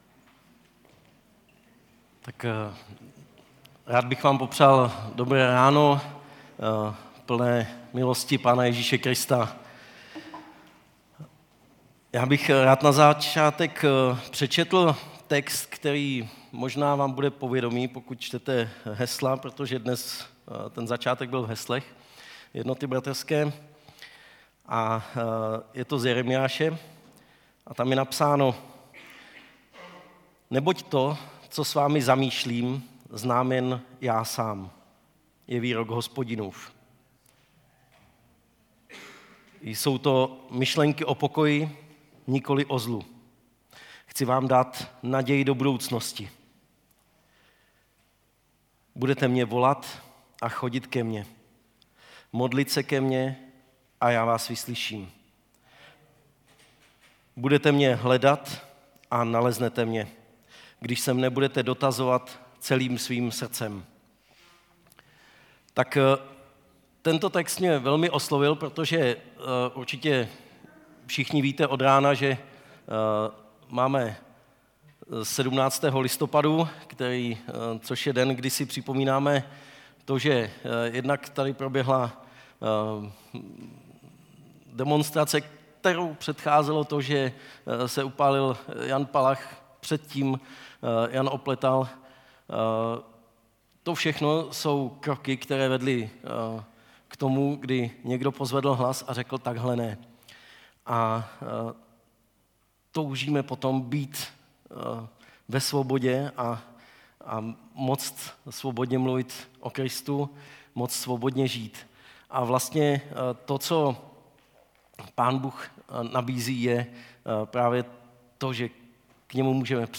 Nedělní bohoslužba